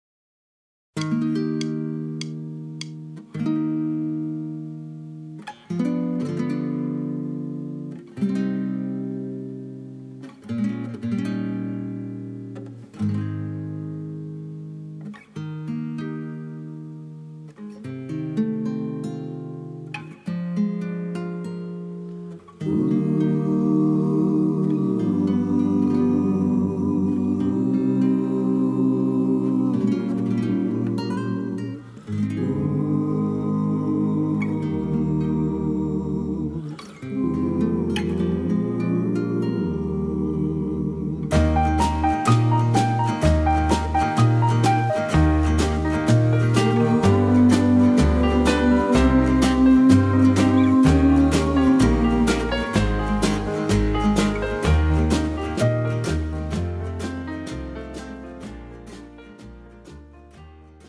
고음질 반주